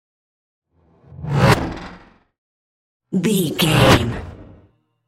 Sci fi vehicle whoosh fast
Sound Effects
Fast
futuristic
whoosh